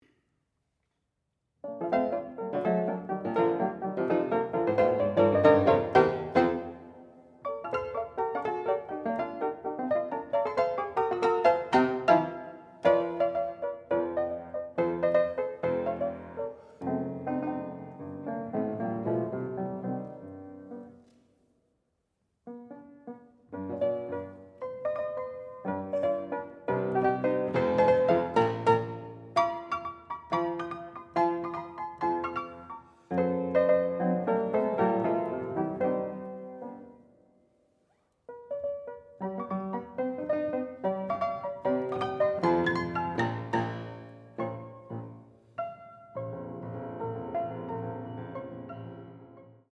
Sound Excerpt 2. Ludwig van Beethoven, Piano Sonata in B-flat major, op. 106, second movement, mm. 1-46: second performance.
fortepiano Nannette Streicher
excerpt from concert at CIRMMT/Montréal baroque, June 22, 2014.